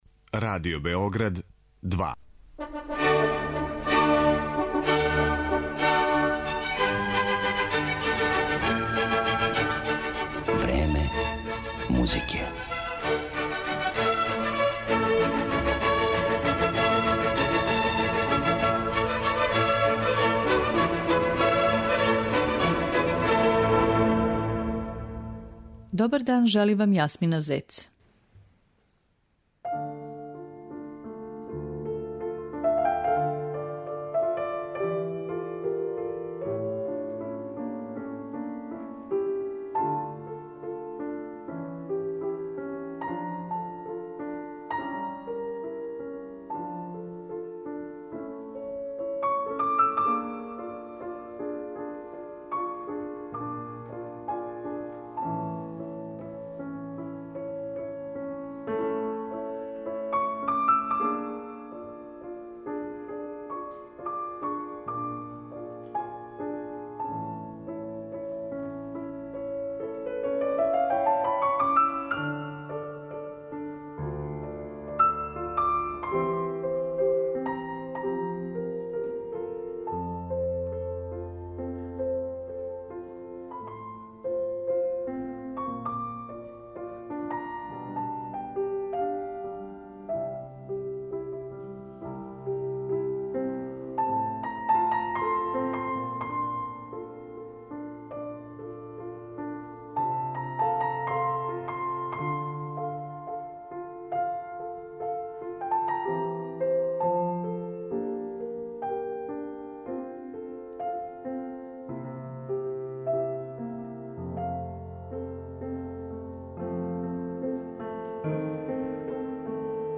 Eмитоваћемо снимке славног руског пијанисте Николаја Демиденка, који од 1990. године живи у Лондону.